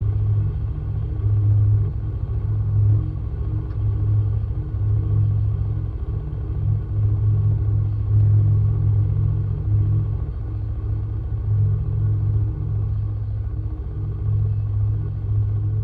bg-hum.mp3